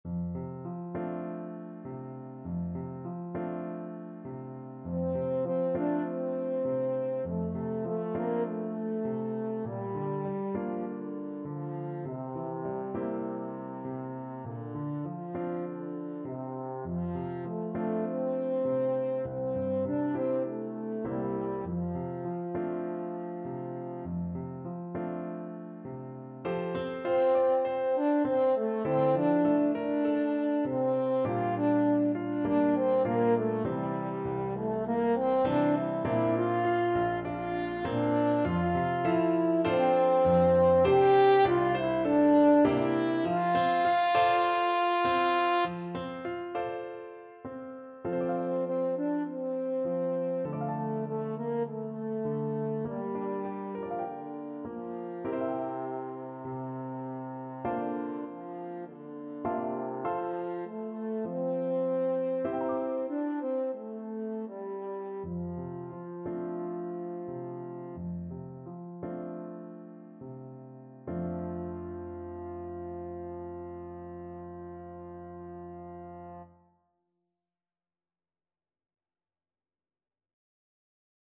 4/4 (View more 4/4 Music)
Gently =c.100